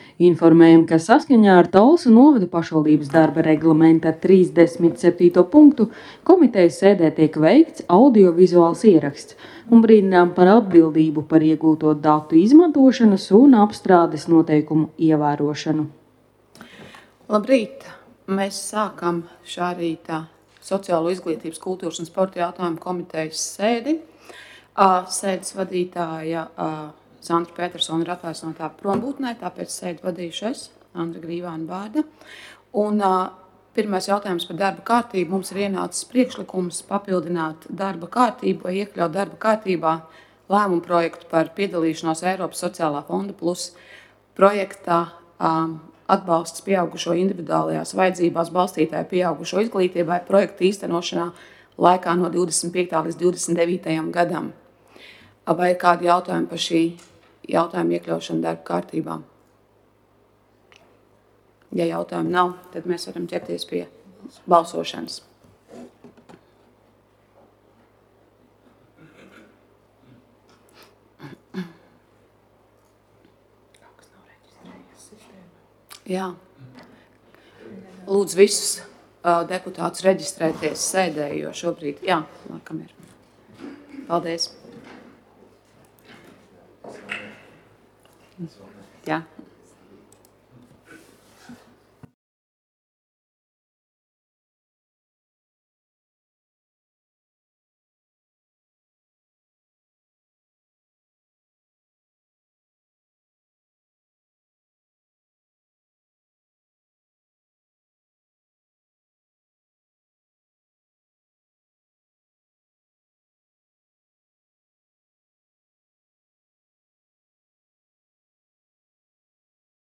Sociālo, izglītības, kultūras un sporta jautājumu komitejas sēde Nr. 3